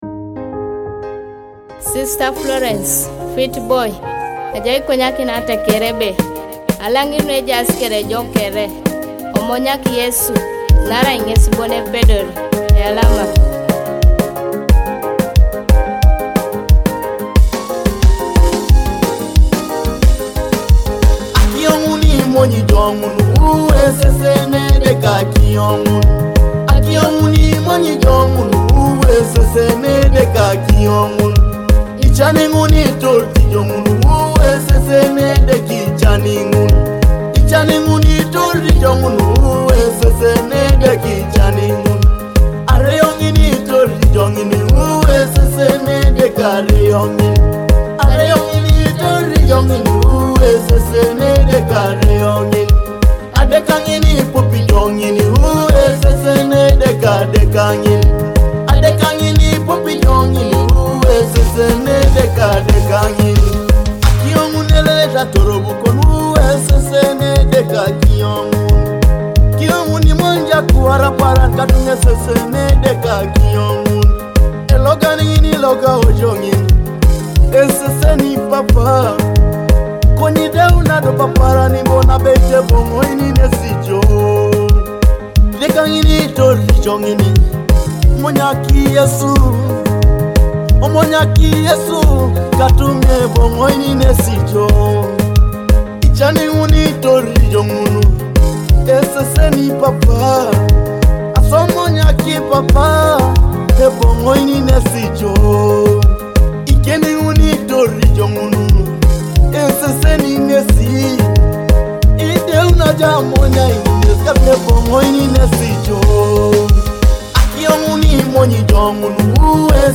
a heartfelt Ateso gospel song meaning “God Sees.”
a powerful Ateso gospel anthem